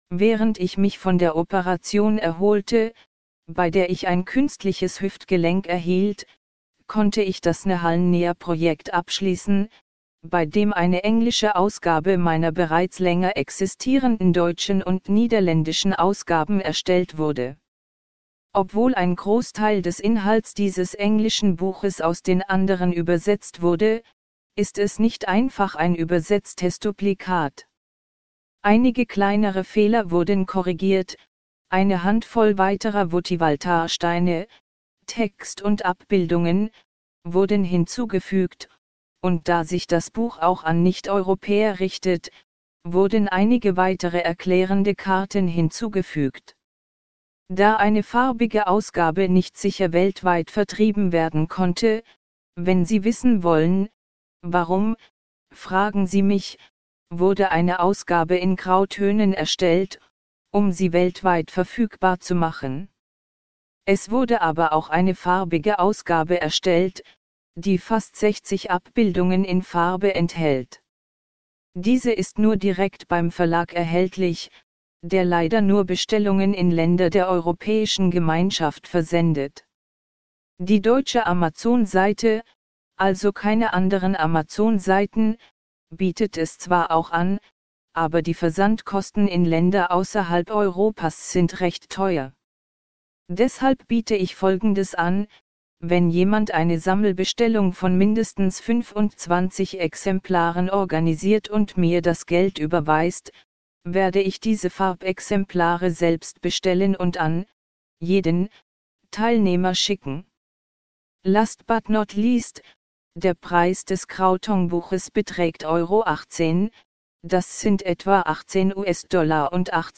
Oder höre den Text, der generiert wurde von den VOVSOFT Text to MP3 Converter